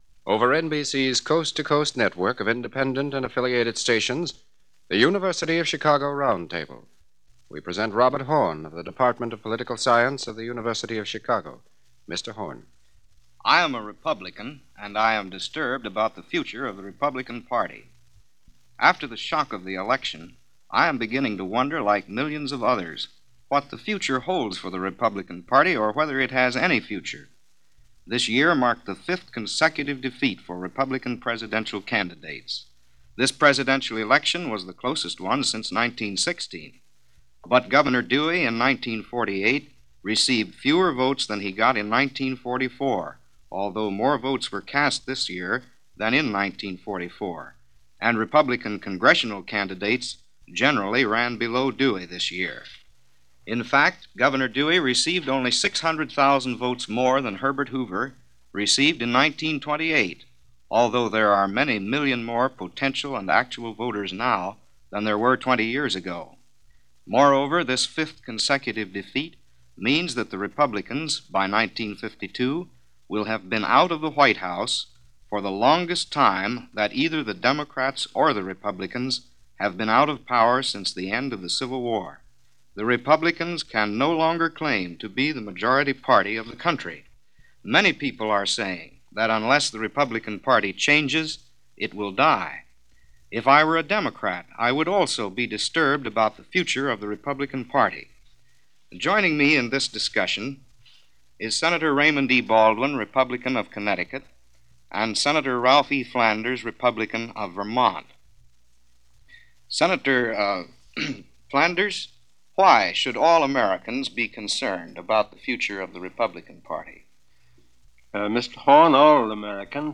Here is one of the radio programs devoted to the retrospective glance. The Chicago University of The Air conducted a roundtable discussion over what happened and what was in store for the future.